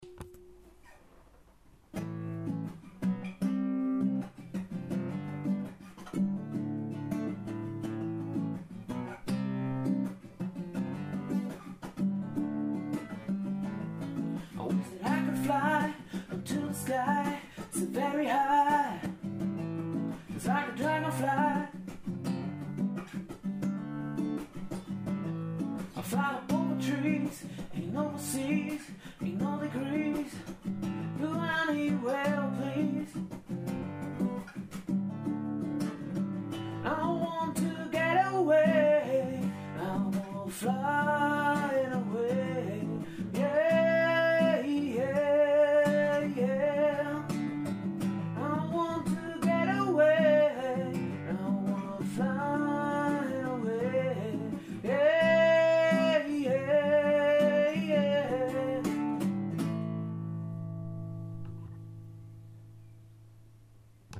1 Stimme, eine Gitarre
schöner Sound im kleinen Rahmen
• Unplugged